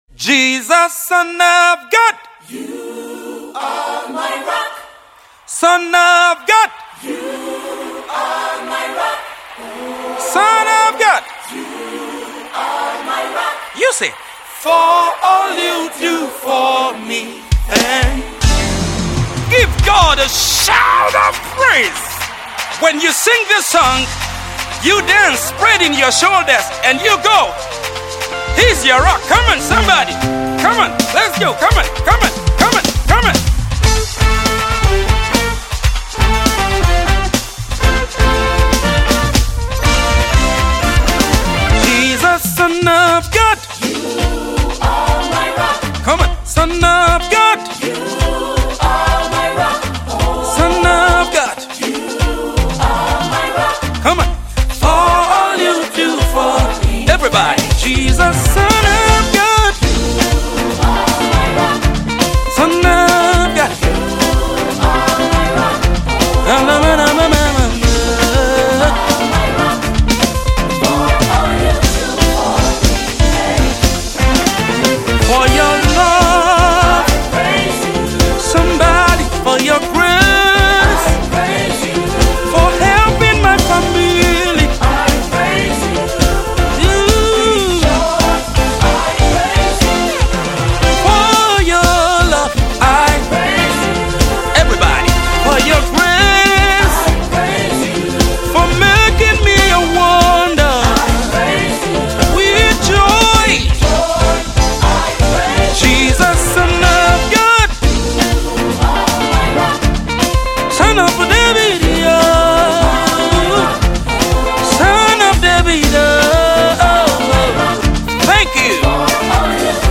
Nigerian Urban Hiphop genre
praise song